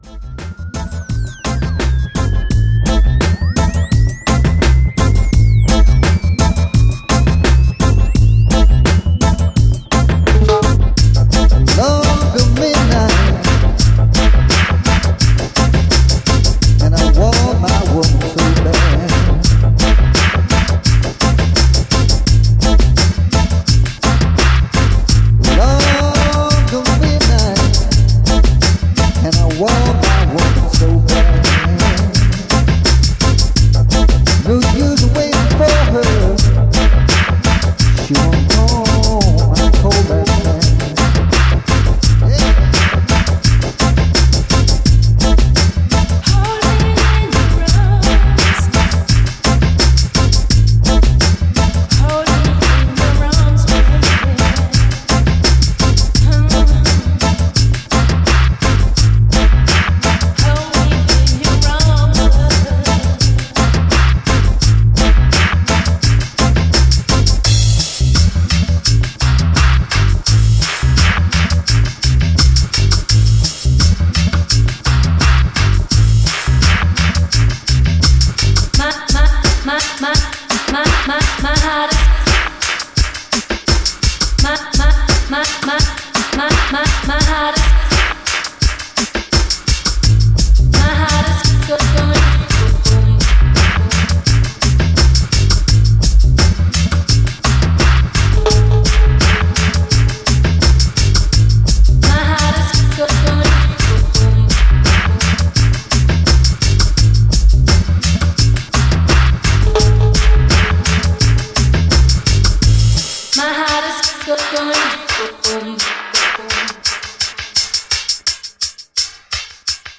son home studio